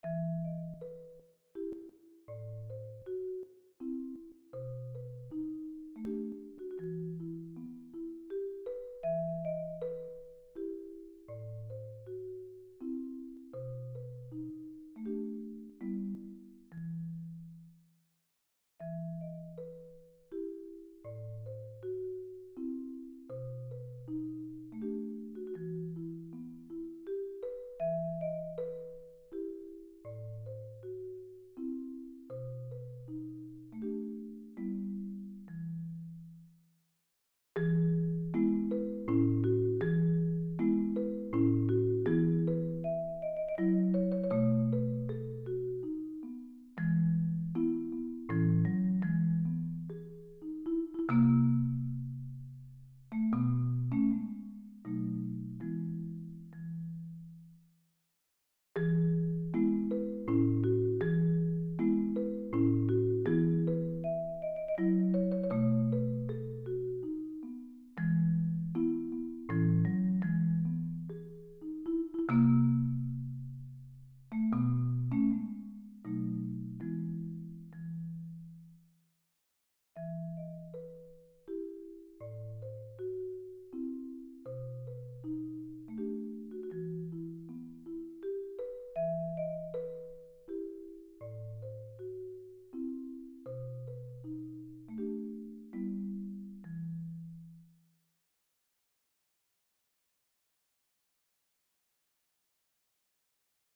Voicing: Marimba Unaccompanied